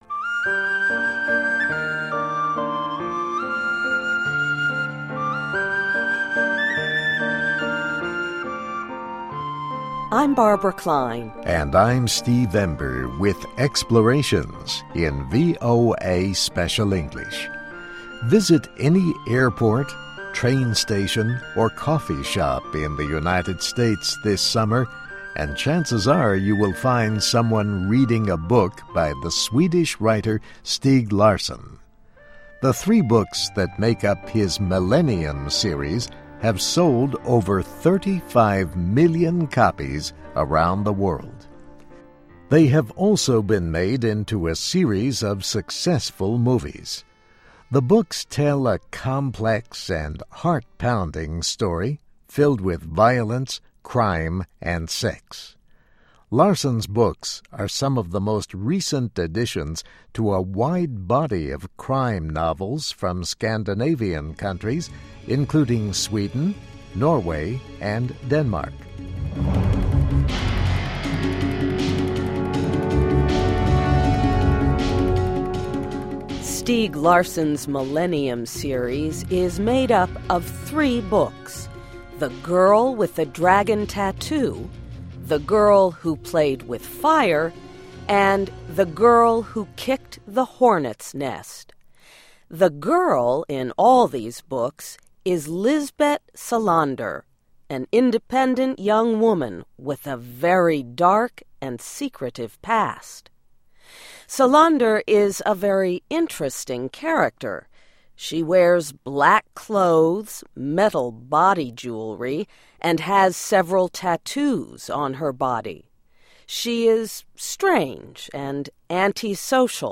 English Listening Practice.